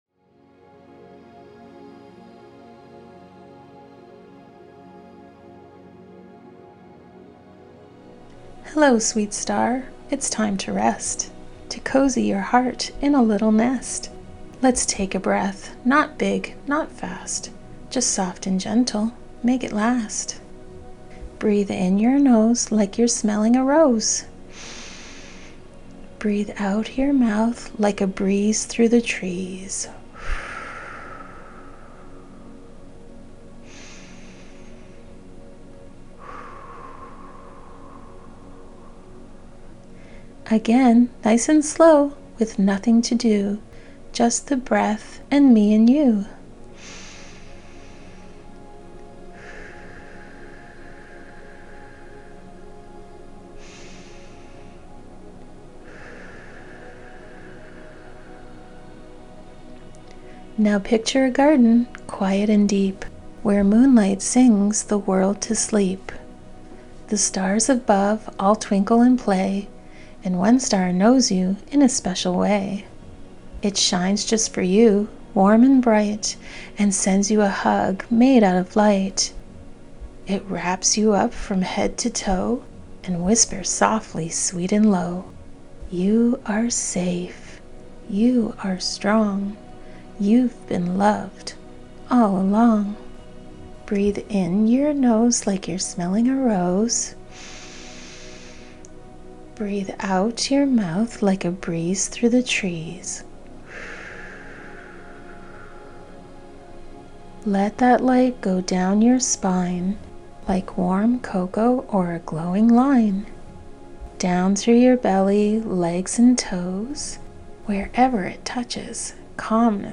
Star Cocoon is a short, rhyming guided meditation designed especially for sensitive souls—children and the inner child in us all. It’s soft, grounding, and gently cosmic.